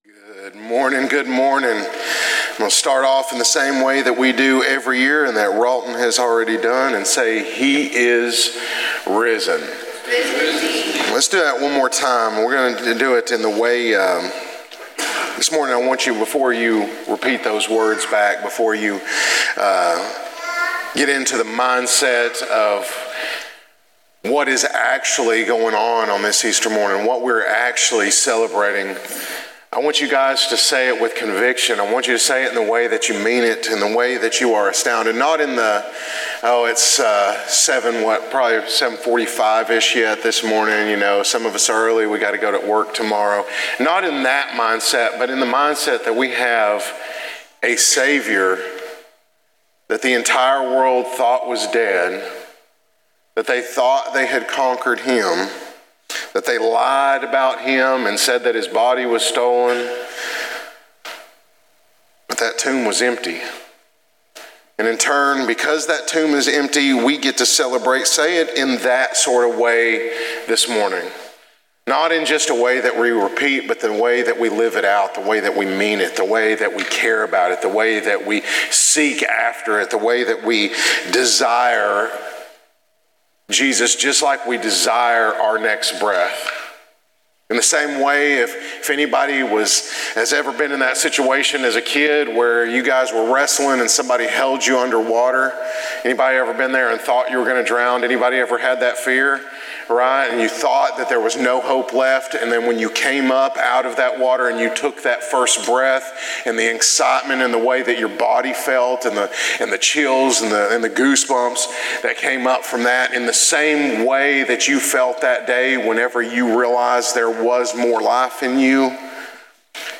Celebrate the resurrection of Christ Jesus with us during this year's Easter Sunrise service.